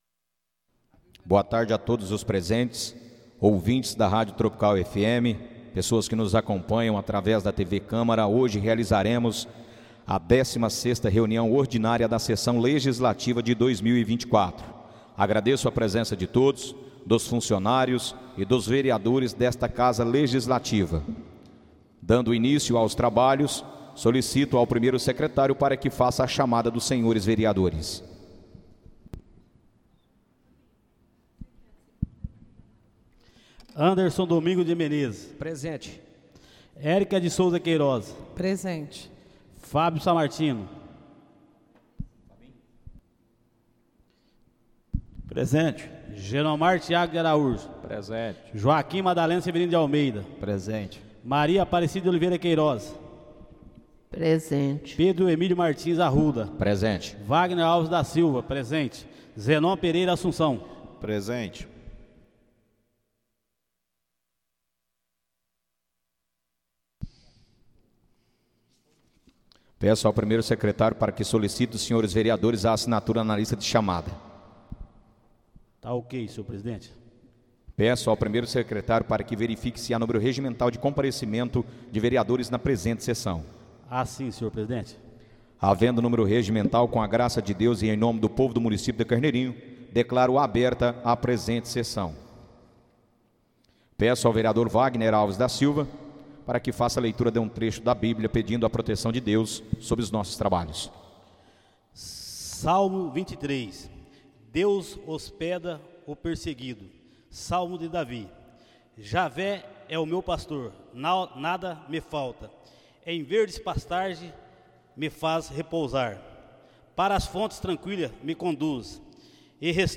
Áudio da 16.ª reunião ordinária de 2024, realizada no dia 14 de Outubro de 2024, na sala de sessões da Câmara Municipal de Carneirinho, Estado de Minas Gerais.